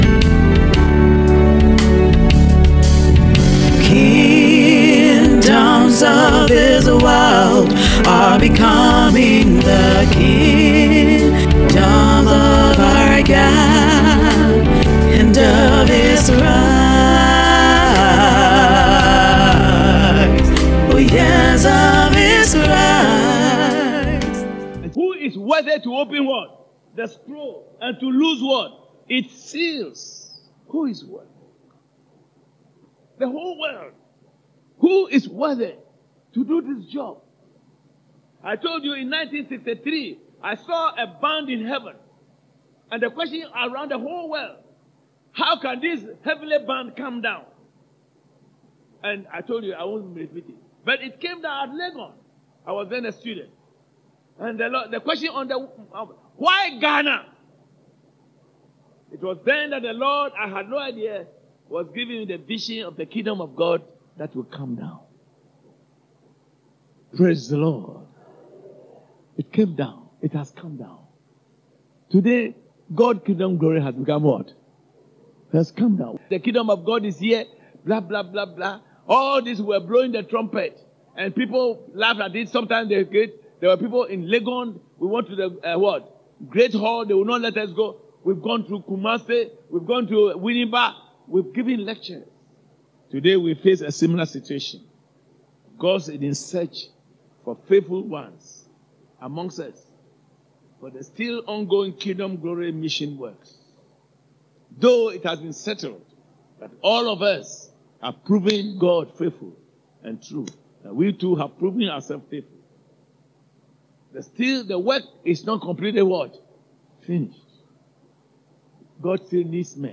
Series: Audio Sermon